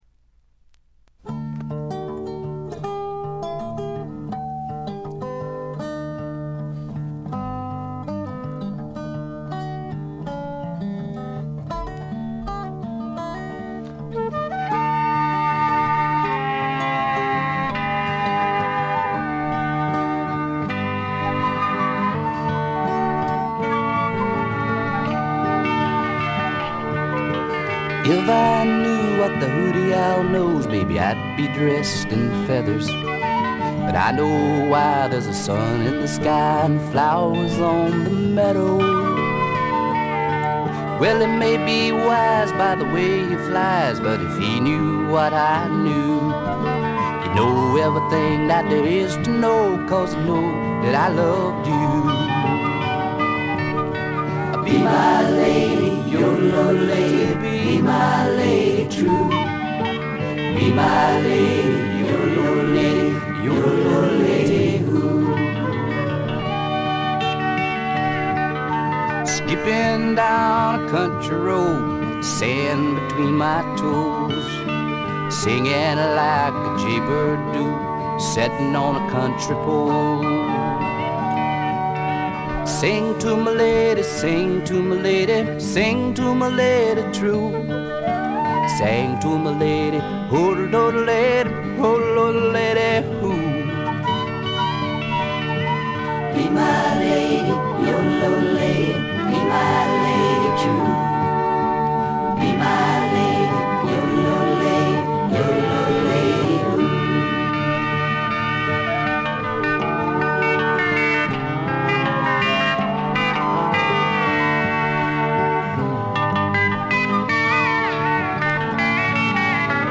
ところどころで軽微なチリプチ。散発的なプツ音少し。
試聴曲は現品からの取り込み音源です。